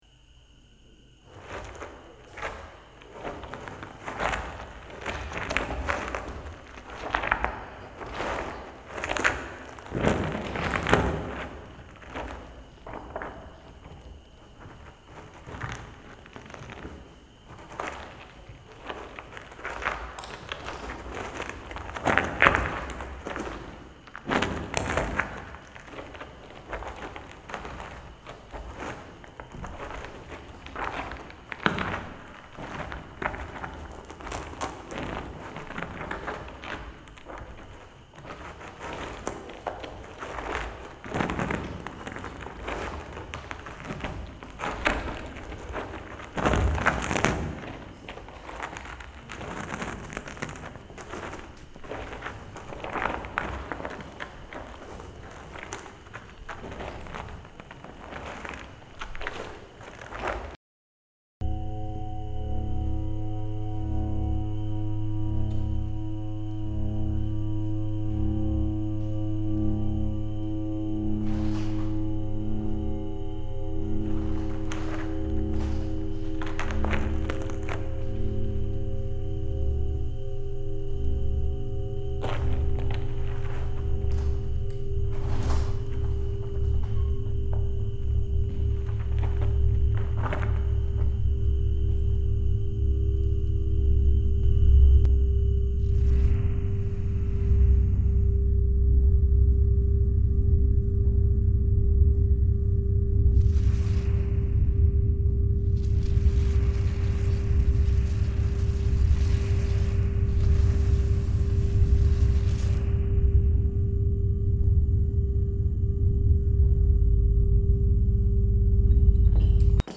Sound/music Location en Studio Interaction Time en Up to 10 minutes Collective en The Netherlands Type of Contribution en Collective Media Netherlands collective _ Starter - Tier 8.mp3